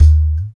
51 TALK DRUM.wav